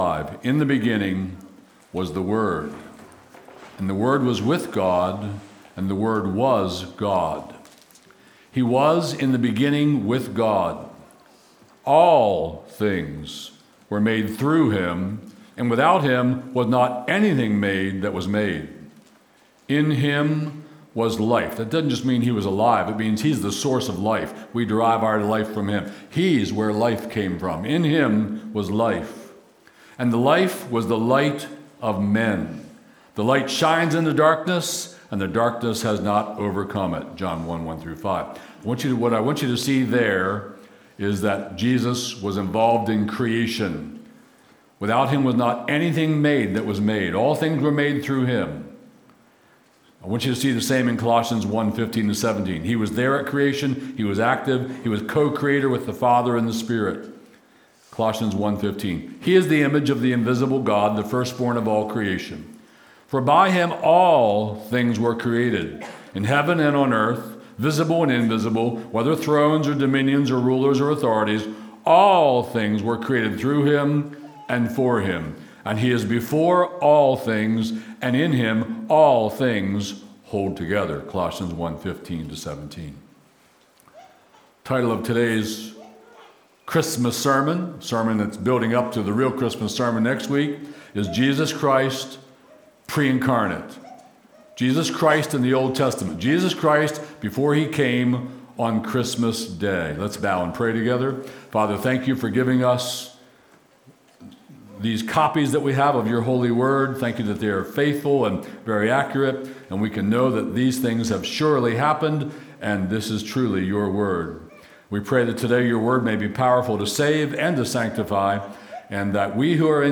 Stream Sermons from Cornerstone Harford County